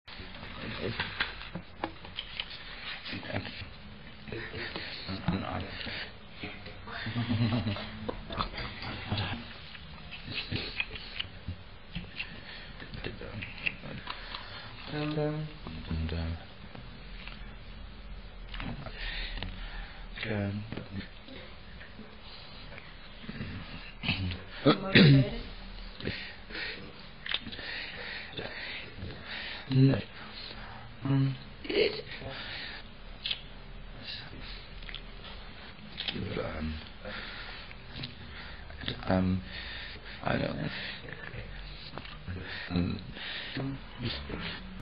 Rotterdam, 2000, Audio CD + installation / 18 minutes / 2000 / stereo / no dialogue
From an interview with Freddie Mercury all spoken words are erased, leaving only the accidental and non-intentional sounds which Mercury makes. Intensely mute and non-verbally communicative.